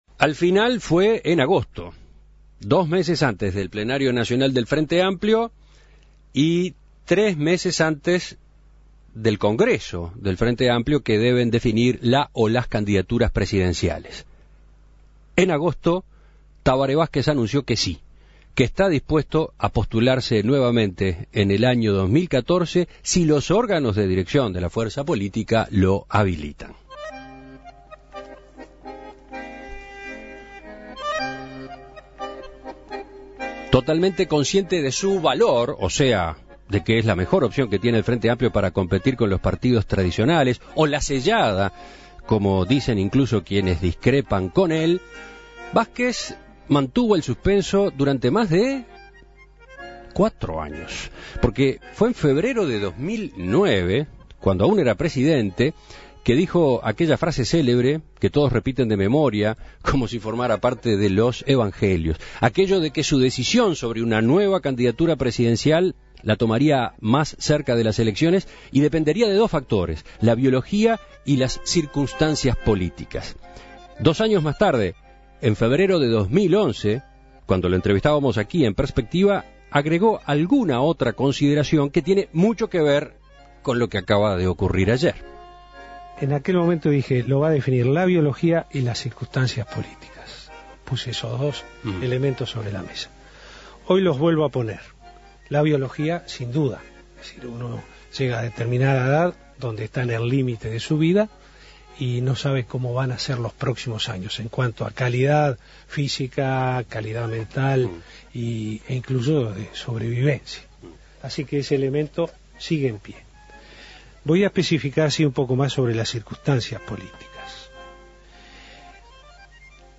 Escuche la entrevista a Juan Castillo